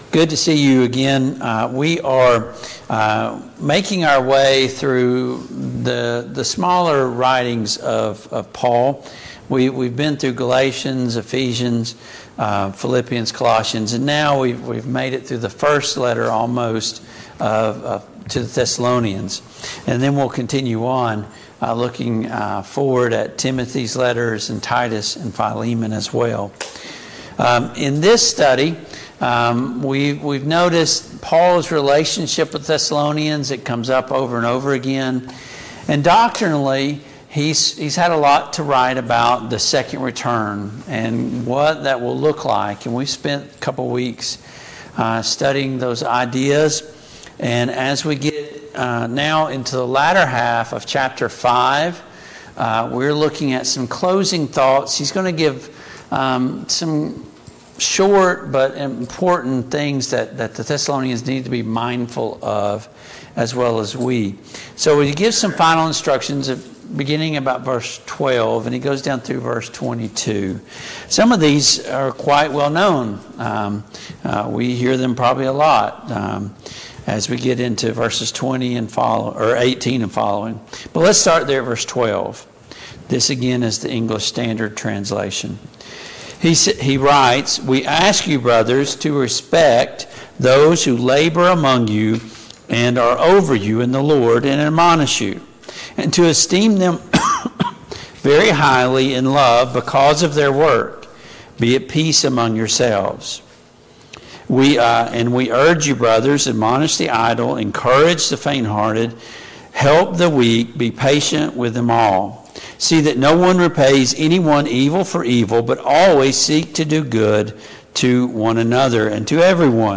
Passage: 1 Thessalonians 5:12-26 Service Type: Mid-Week Bible Study